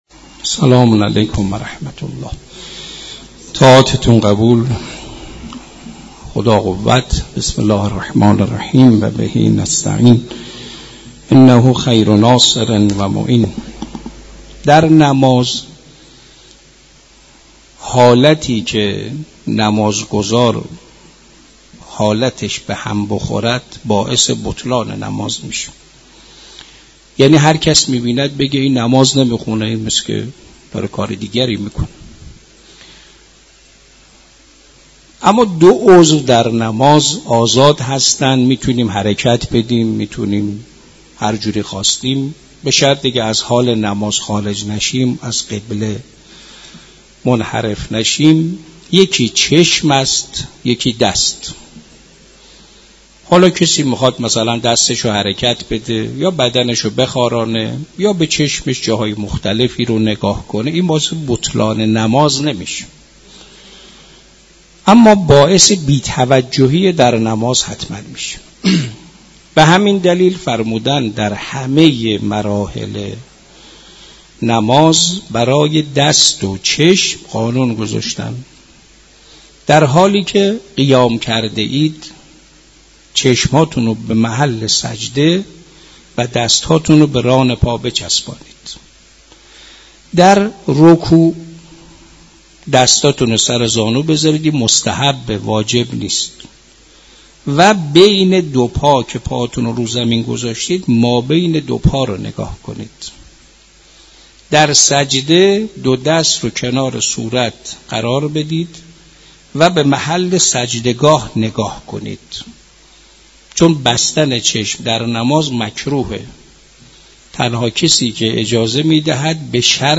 در مسجد دانشگاه